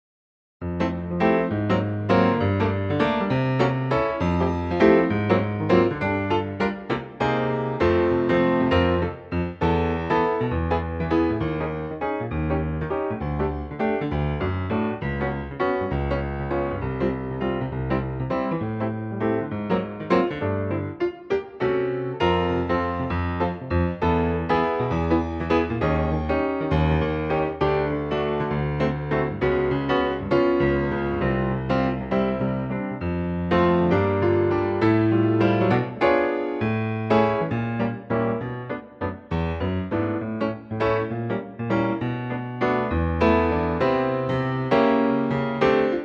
Unique Backing Tracks
key - F - vocal range - C to A (optional C top note)
in waltz time, arranged for piano only.